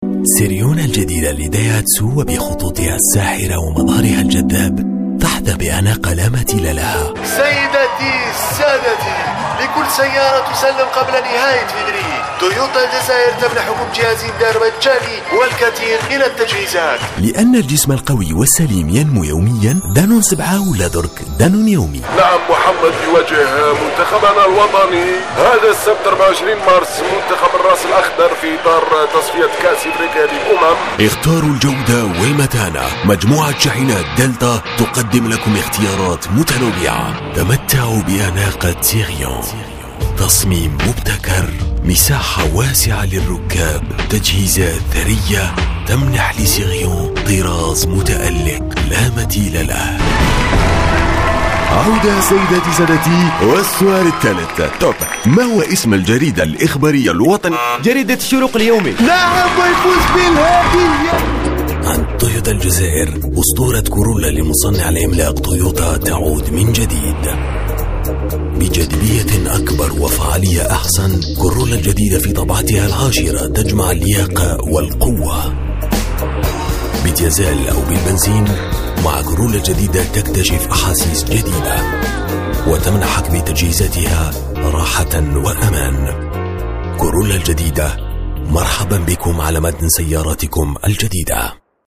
Comédien voix off en langue arabe, réalisateur radio, chanteur, imitateur depuis plus de 20 ans, je met chaque jour ma voix et mon expérience à votre service pour tout enregistrement de voix : pub radio ou TV, commentaire de film institutionnel, film d\'entreprise, habillage d\'antenne, audiotel, billboard, composition, cartoon, comédie radio, bande annonce, signature, attente téléphonique, jingle, voice over....
Sprechprobe: eLearning (Muttersprache):